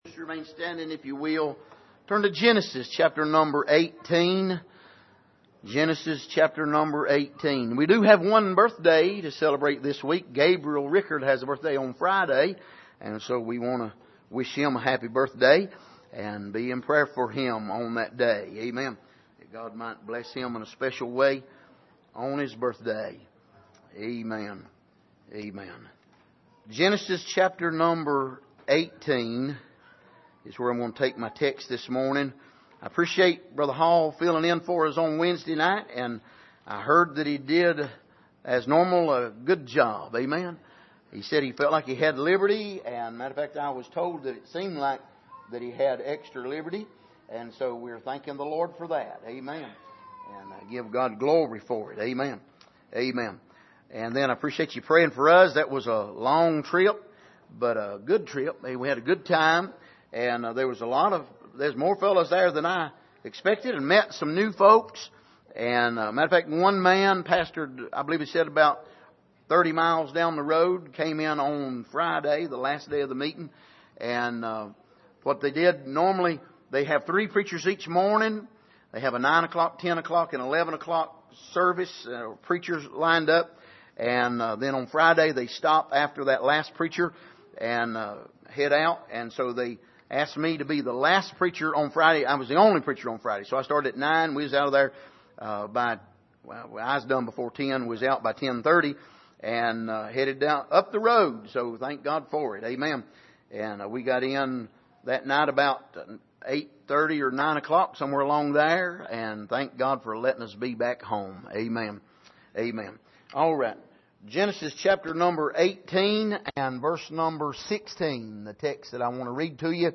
Passage: Genesis 18:16-18 Service: Sunday Morning